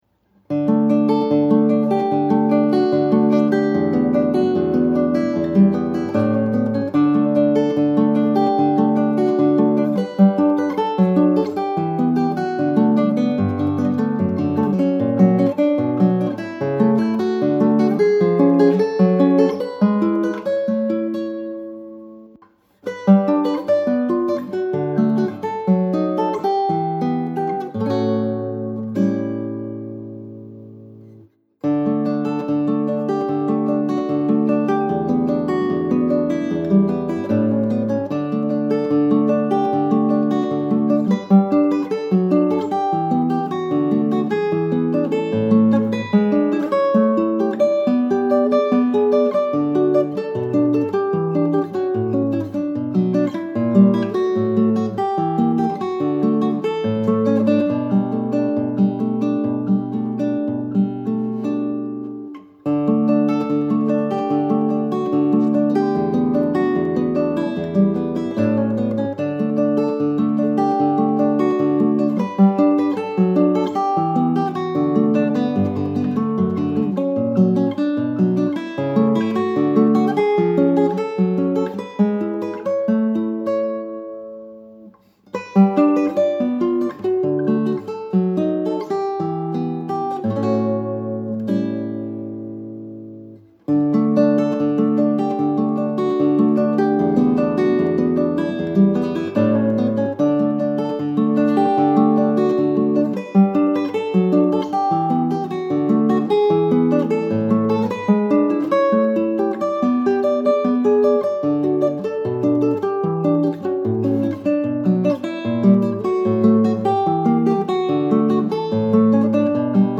pour guitare seule